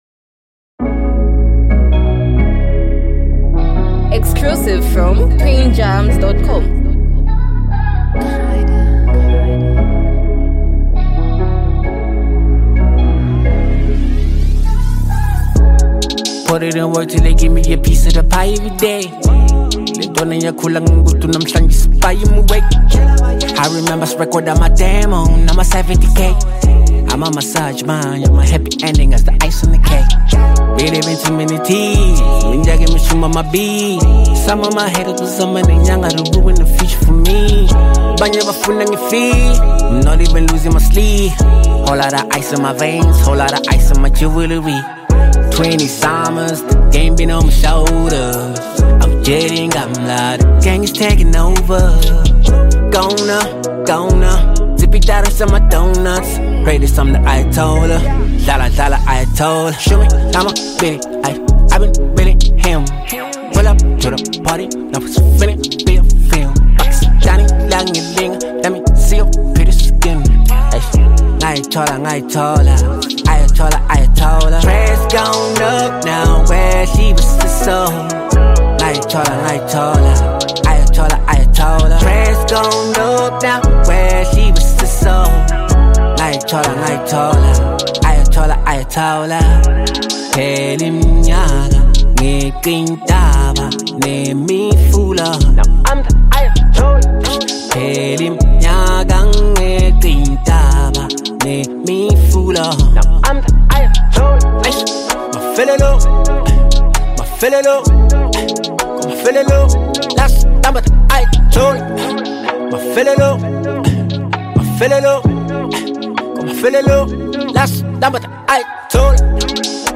South African rap icon
anthem